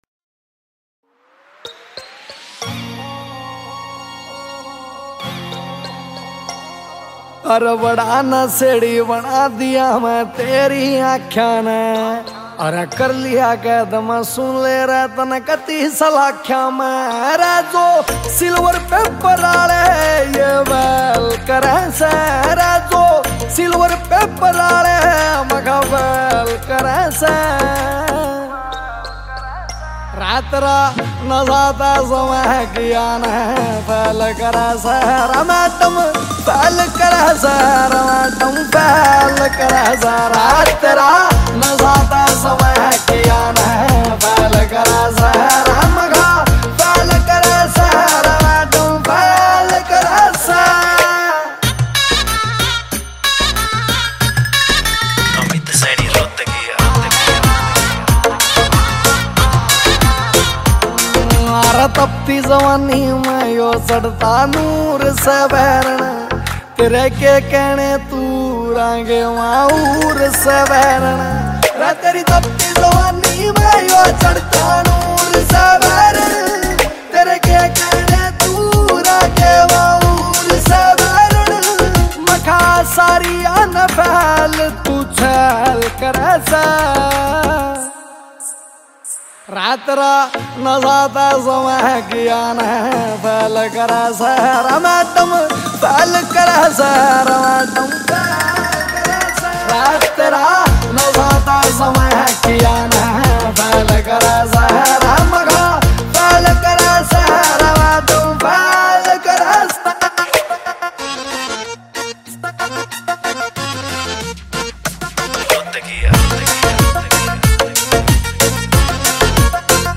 Haryanvi Single Tracks